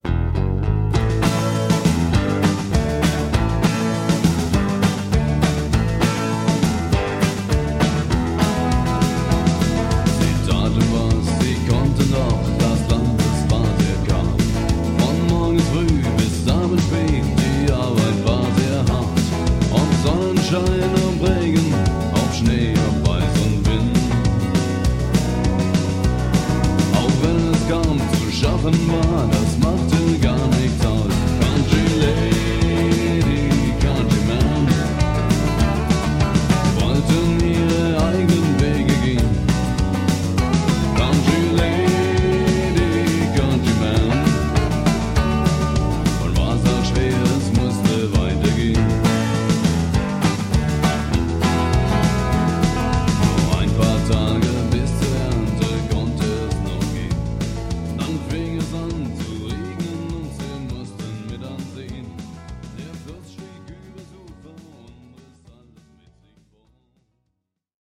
Country Lady (Country)